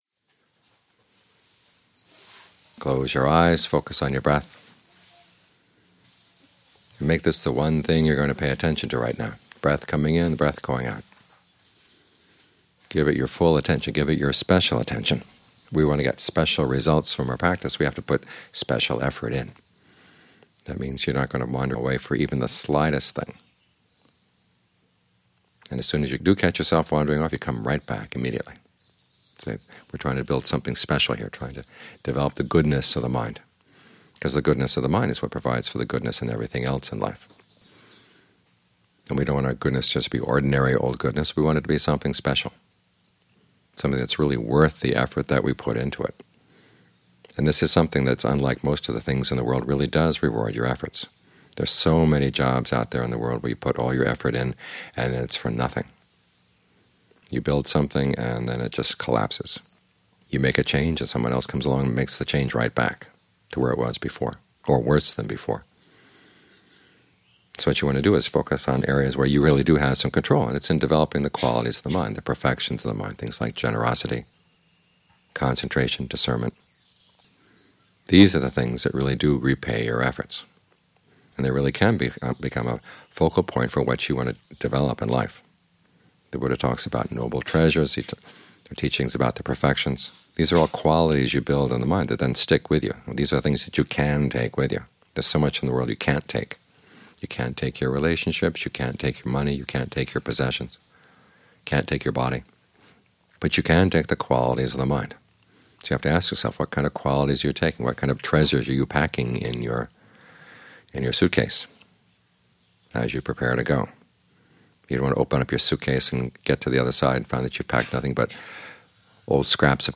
Morning Talks